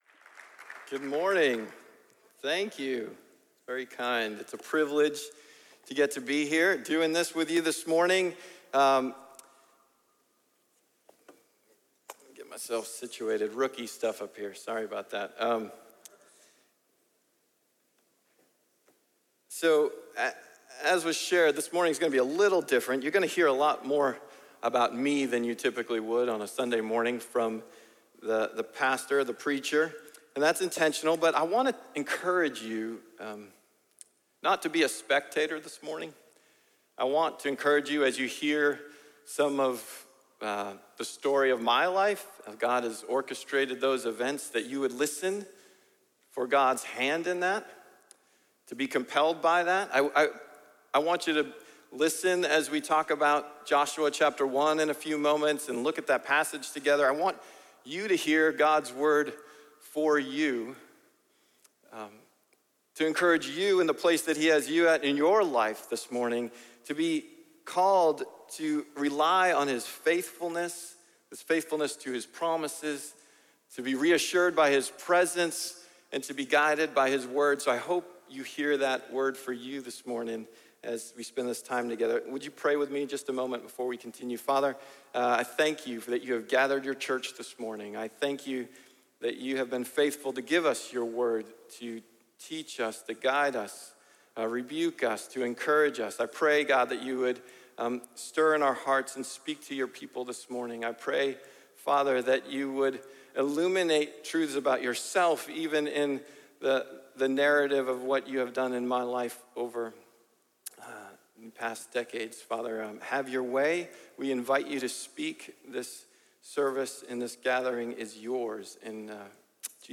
A message from the series "Guest Speakers."